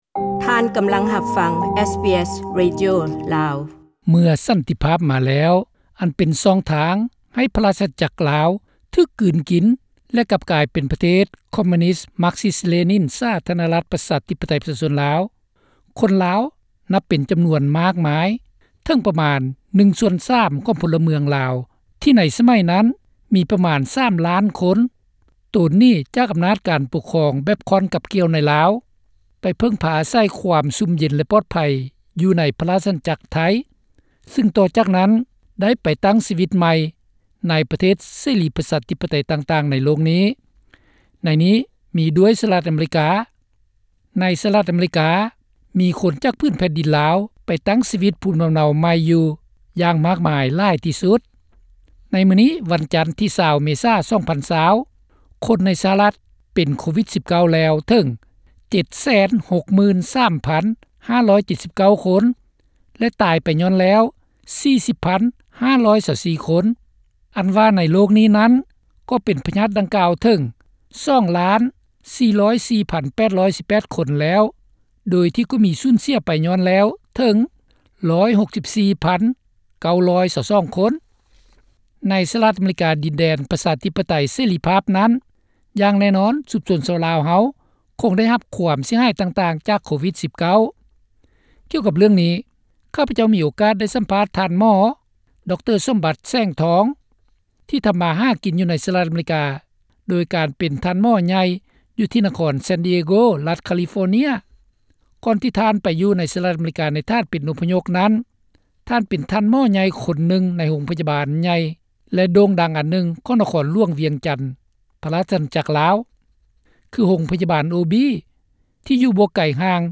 ໂກວິດ-19 ກັບຄົນລາວໃນສະຫະຣັດອະເມຣິກາ (ສາພາດ